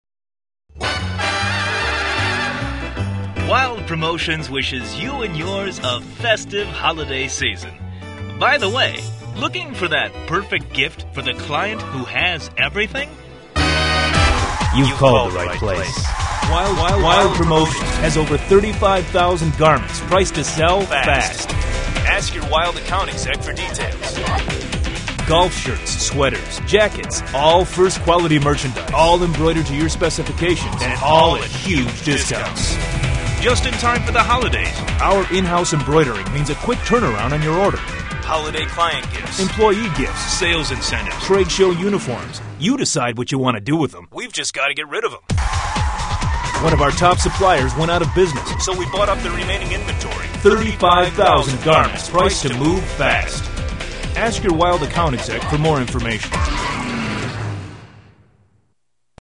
Wild Promotions Holiday On-Hold Messaging